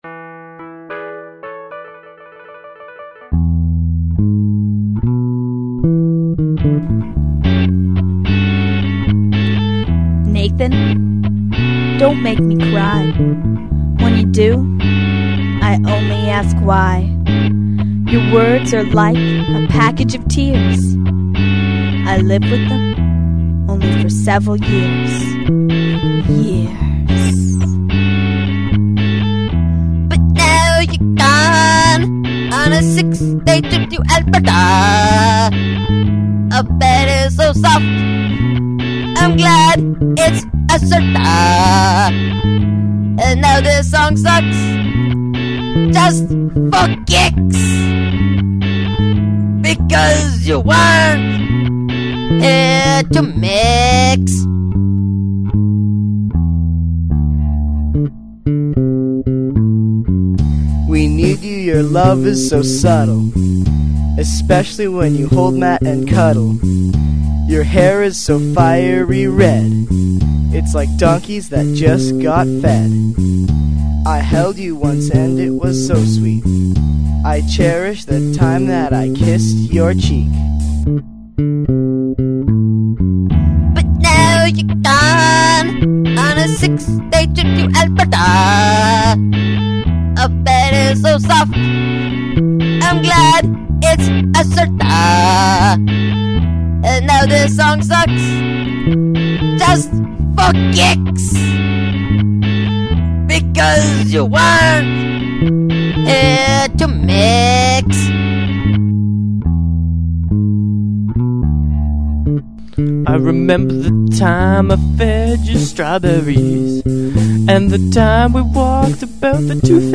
Drums
Guitar
Vocals
Keyboard